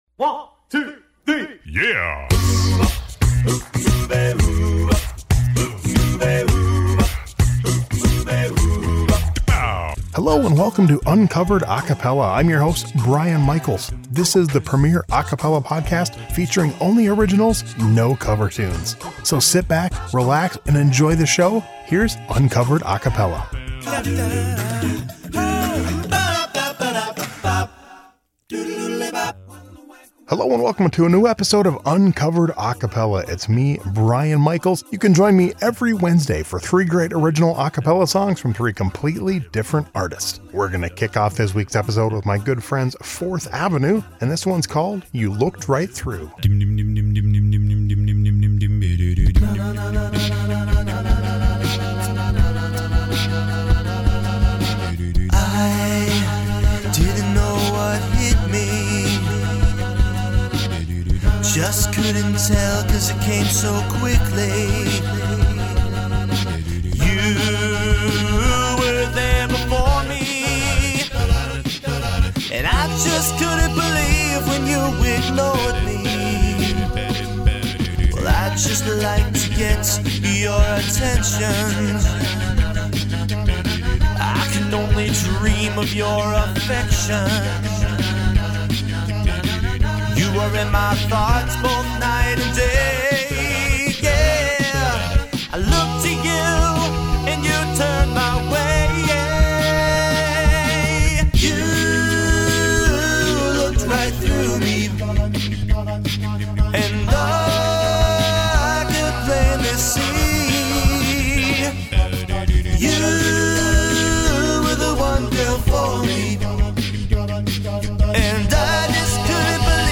3 original a cappella songs every week!